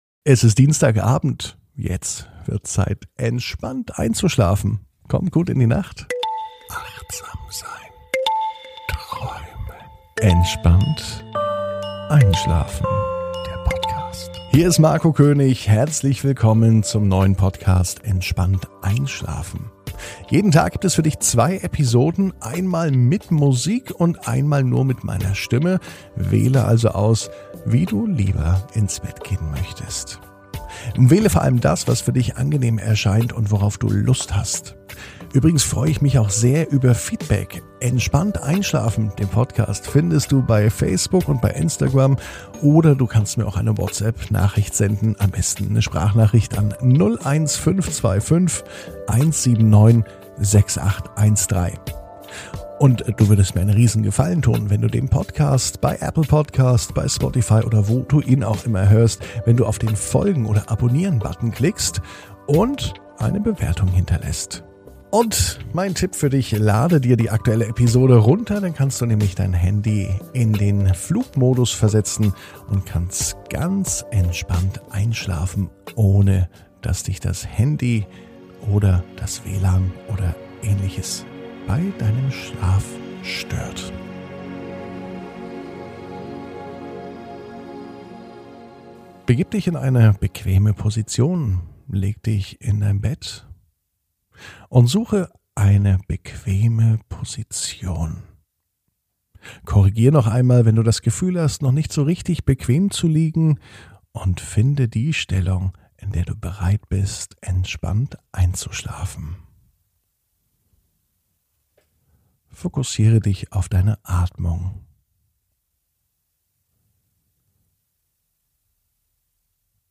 (ohne Musik) Entspannt einschlafen am Dienstag, 20.04.21 ~ Entspannt einschlafen - Meditation & Achtsamkeit für die Nacht Podcast